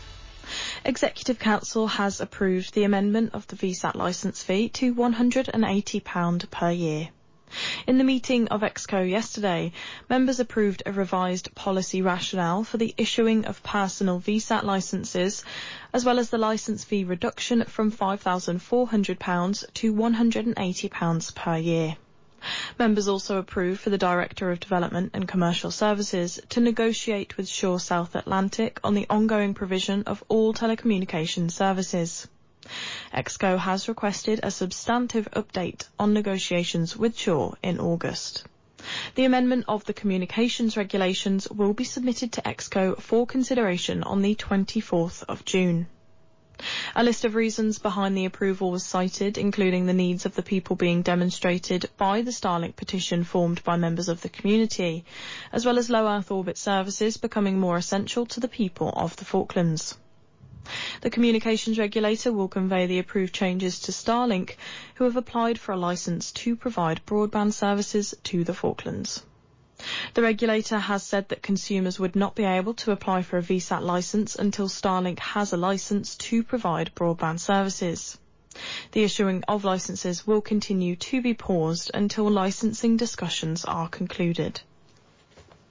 June 10th 2015; Falkland Islands radio newscaster announcing the change to VSAT licensing policy and license cost (Thank you, FIRS)